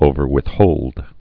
(ōvər-wĭth-hōld, -wĭth-)